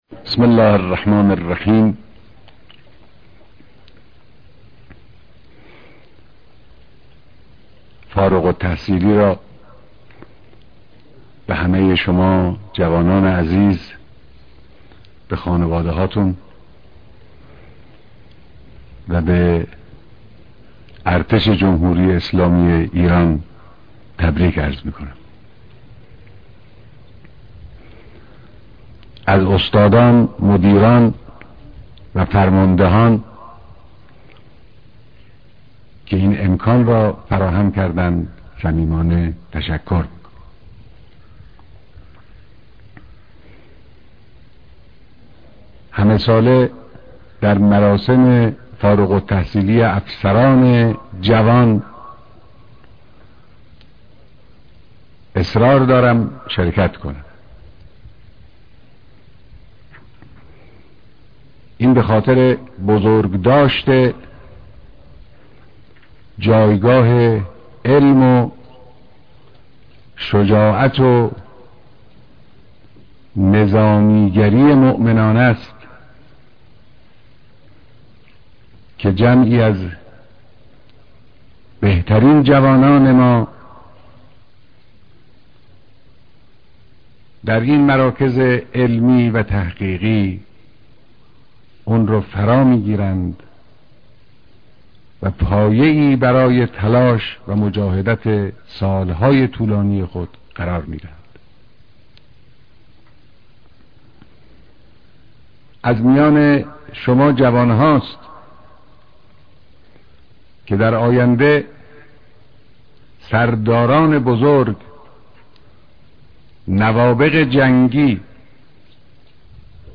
بيانات در مراسم مشترك دانشآموختگى دانشجويان دانشگاههاى سهگانهىارتش